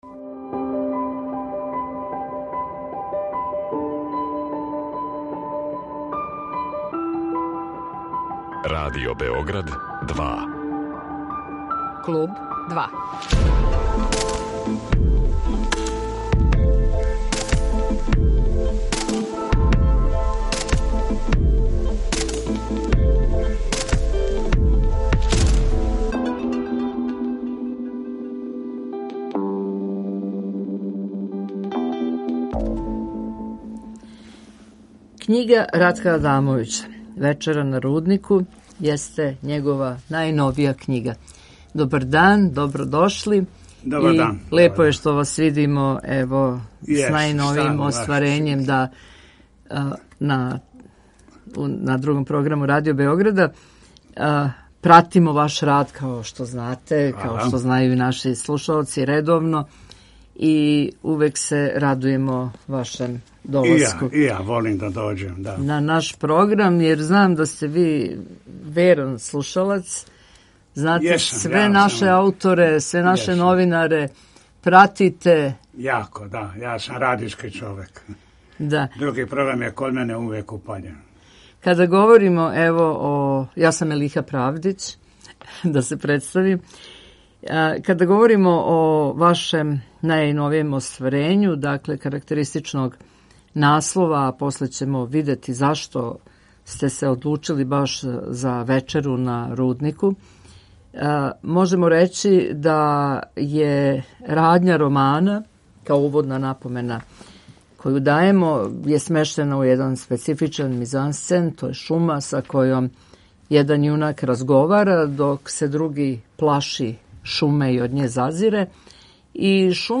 Гост Клуба 2 је књижевник Ратко Адамовић а говоримо о његовом најновијем роману "Вечера на Руднику".